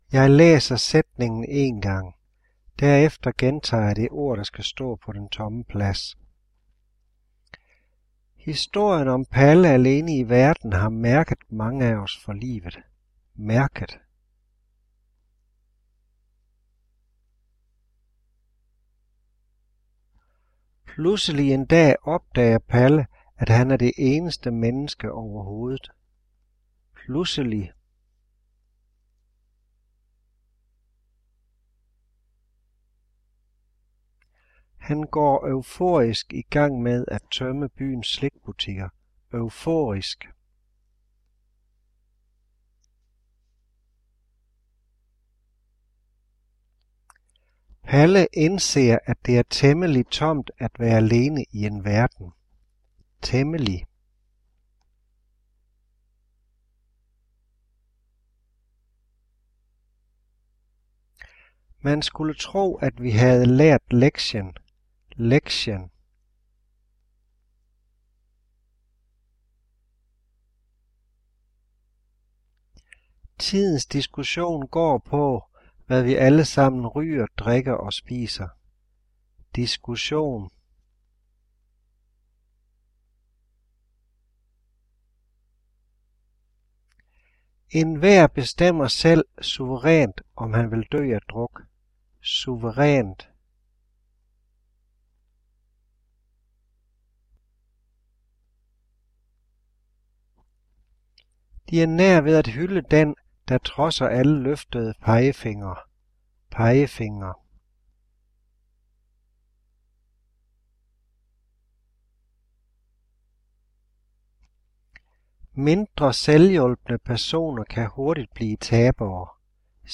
Ensomme Palle - Diktat
Ensomme palle diktat.mp3